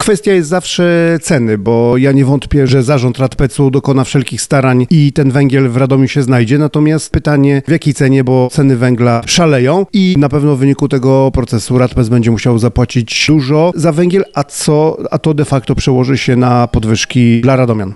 Ponadto istnieje zagrożenie, że zimą może zabraknąć węgla. Radpec jest zdeterminowany, aby zapewnić odpowiednie ilości węgla na sezon jesienno-zimowy, mówi Radosław Witkowski, prezydent Radomia: